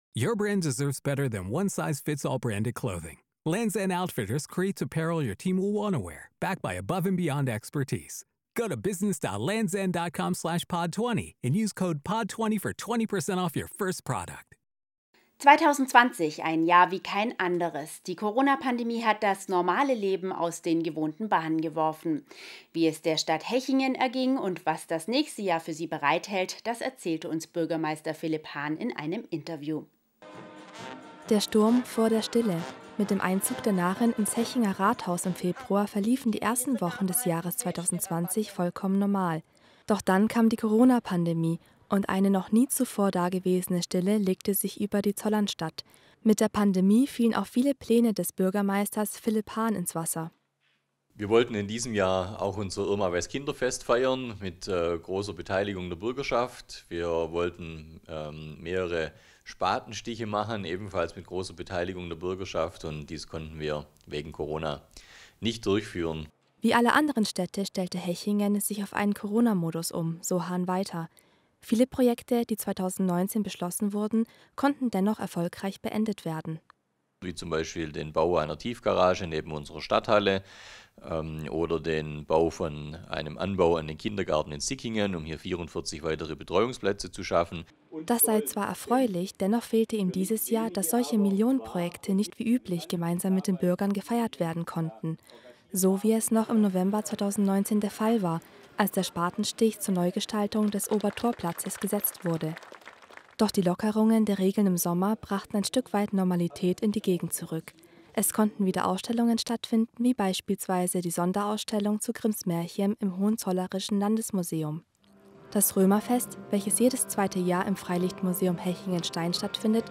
Rückblick 2020 & Ausblick: Hechingen | Interview mit Bürgermeister Philipp Hahn